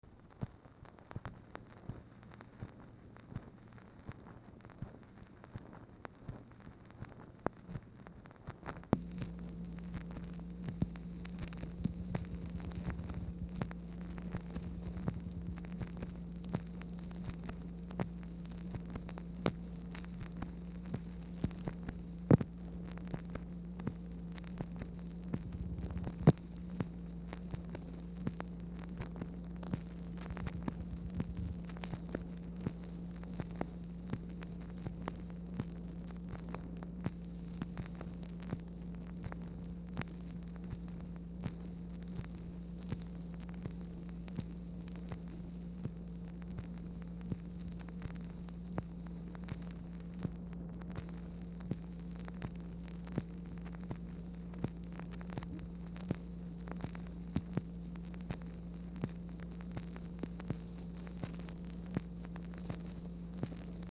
Telephone conversation # 4443, sound recording, MACHINE NOISE, 7/30/1964, time unknown | Discover LBJ
Format Dictation belt